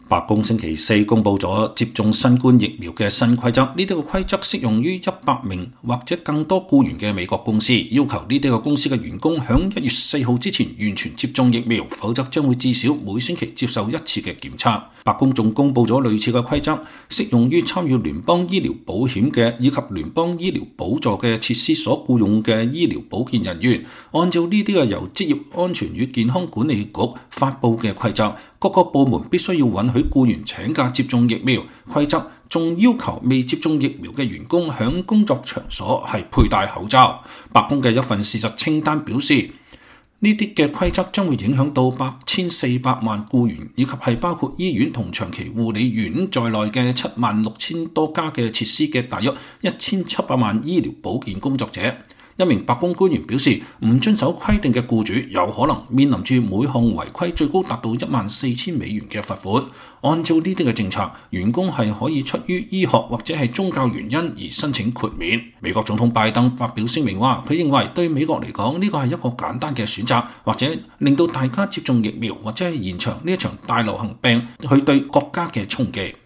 拜登在白宮談批准為5到11歲的兒童接種新冠疫苗。